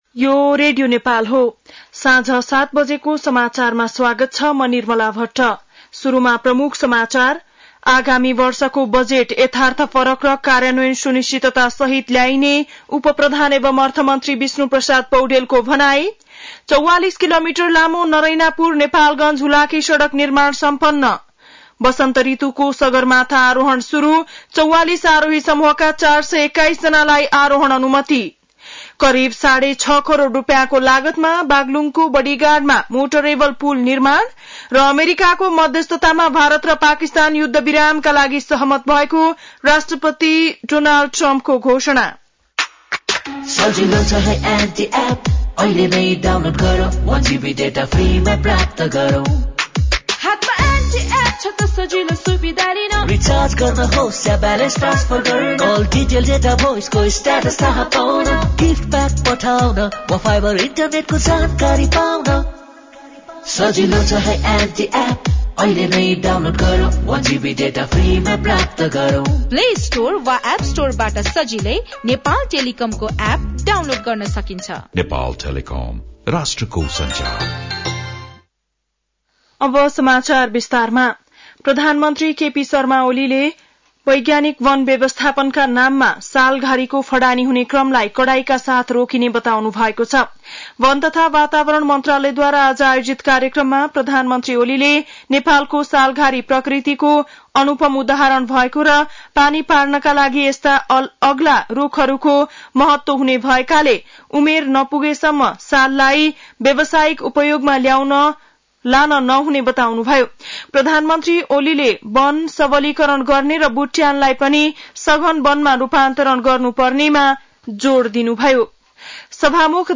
बेलुकी ७ बजेको नेपाली समाचार : २७ वैशाख , २०८२